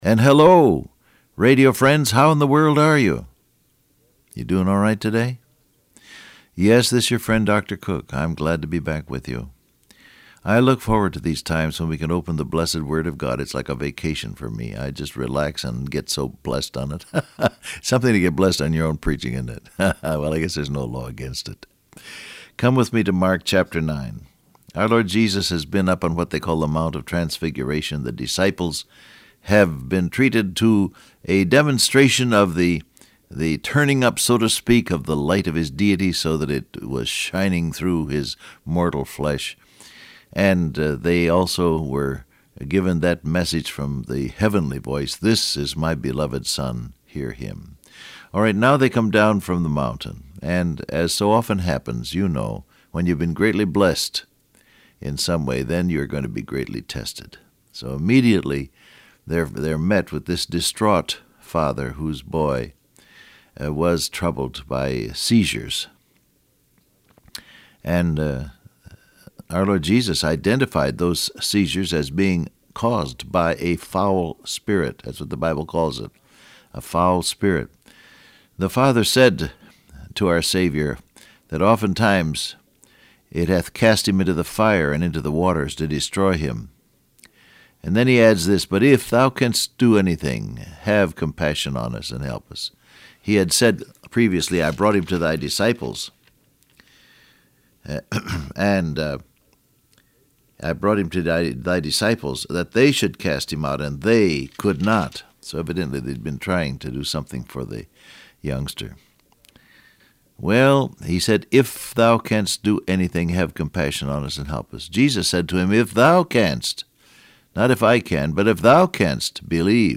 Download Audio Print Broadcast #6454 Scripture: Mark 9:28 , Matthew 17, John 9:4, Ezekiel 3:27 Topics: Prayer , God's Word , Fasting , Unbelief Transcript Facebook Twitter WhatsApp And hello radio friends!